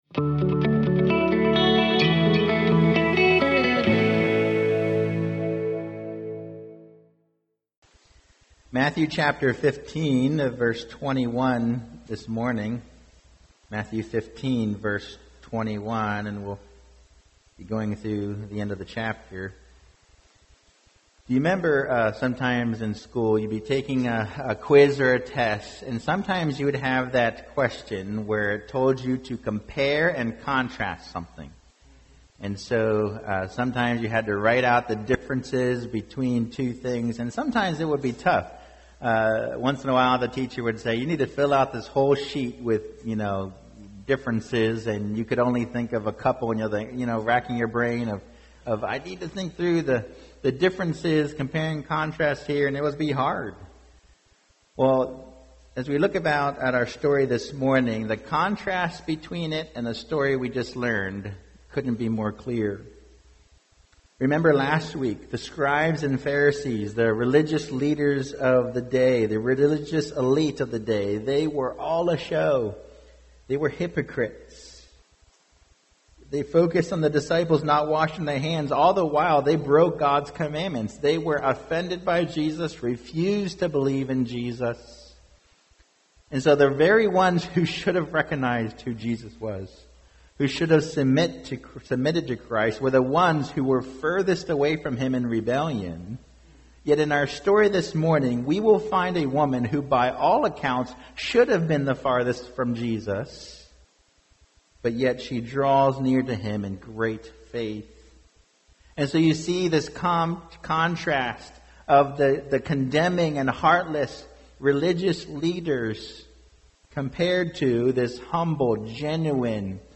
2023 Bread at the Master’s Table Preacher